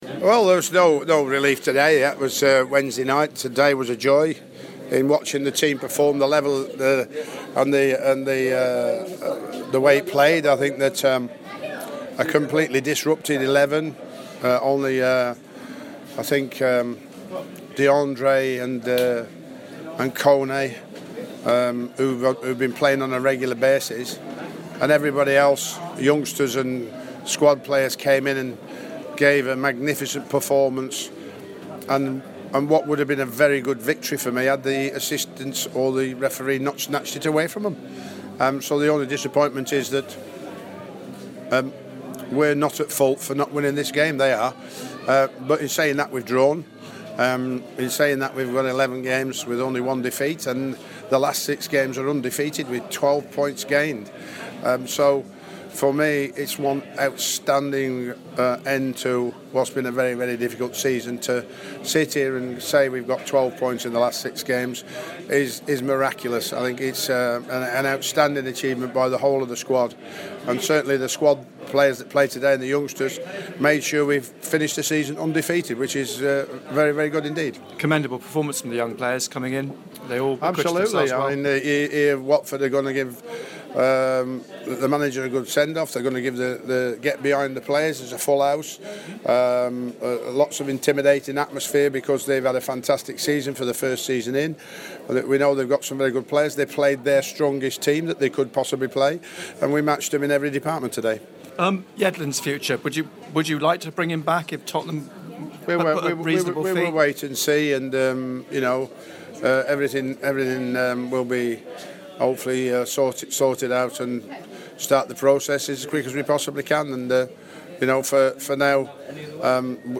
Sam Allardyce spoke to BBC Newcastle following the 2-2 draw with Watford.